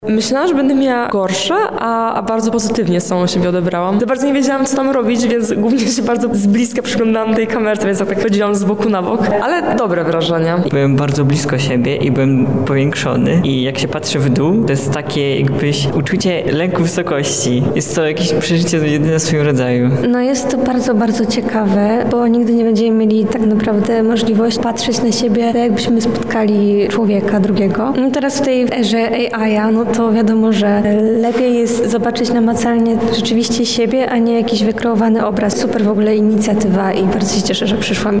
sonda
Refleksje-widzow.mp3